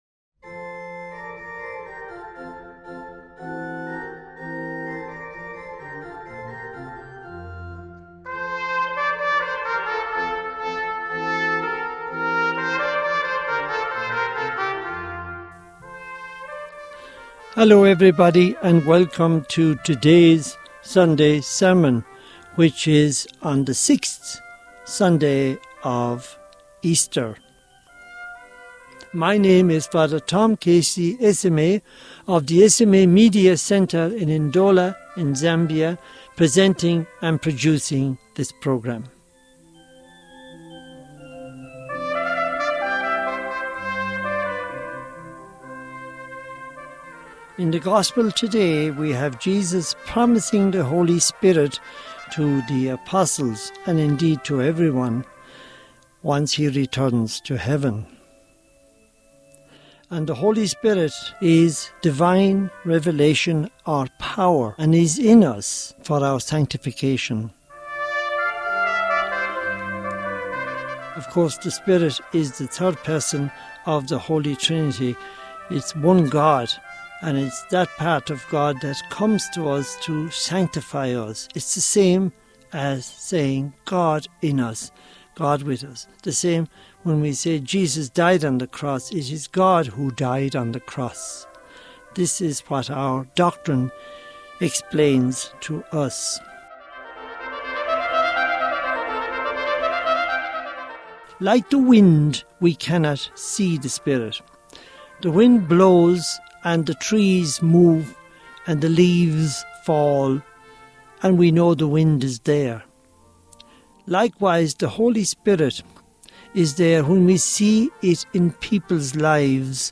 Homily for the 6th Sunday of Easter 2025 | Society of African Missions